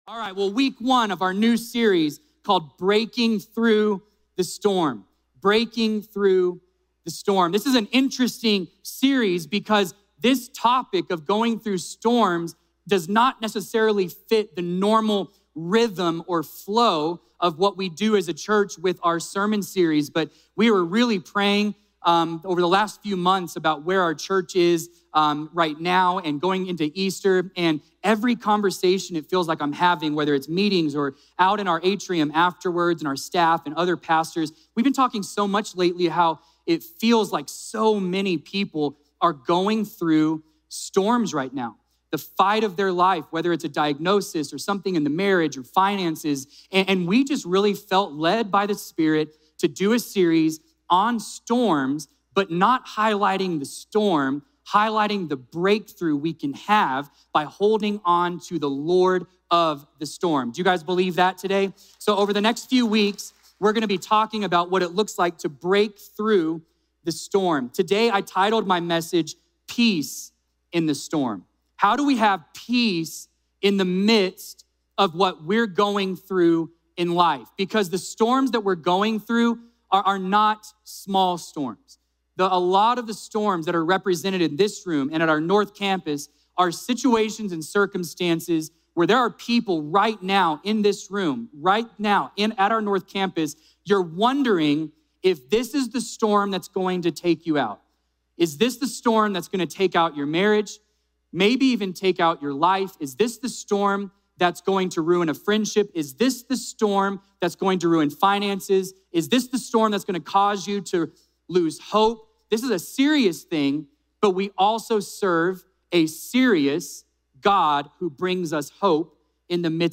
Subscribe to the Citizen Church Podcast and automatically receive our weekly sermons.